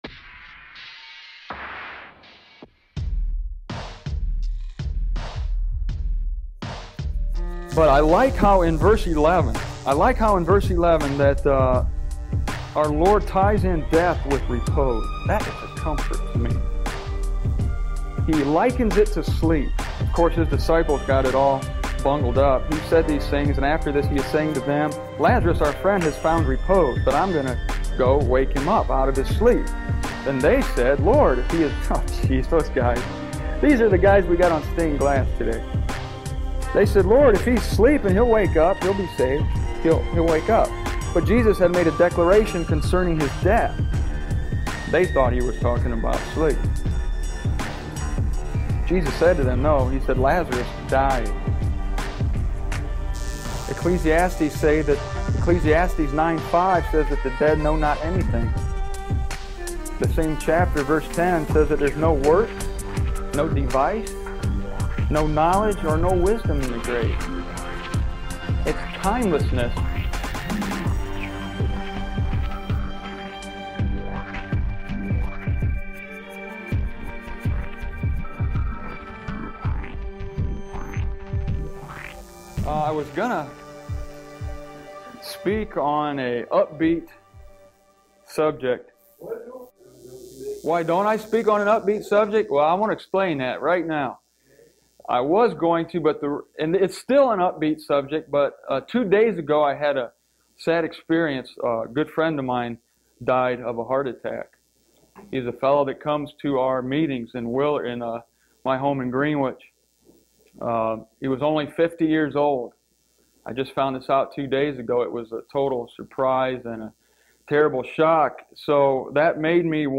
MZ/IB Archive - Purpose and Plans Series Lazarus and the Resurrection Dear Fellow Believers, I recorded this message in Lansing, Michigan, in the summer of 1996, and it has been probably twenty-five years since I last heard it.